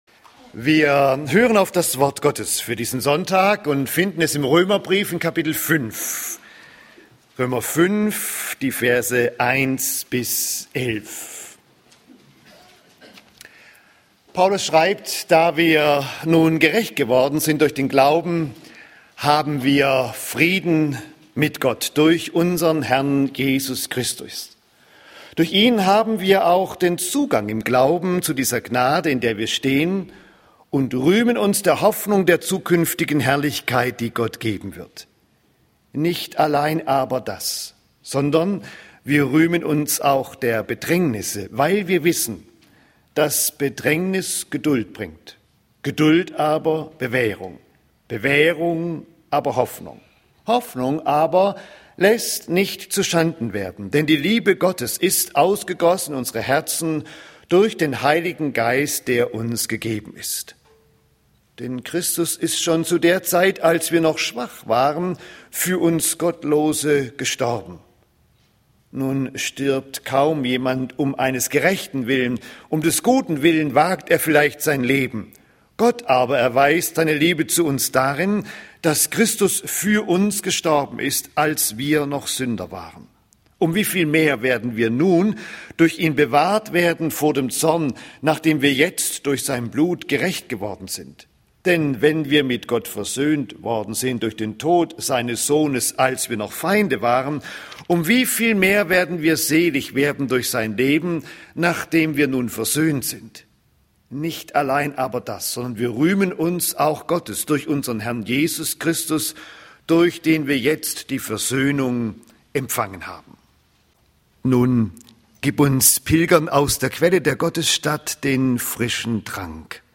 Mehr Gewissheit geht nicht (Rö. 5, 1-11) - Gottesdienst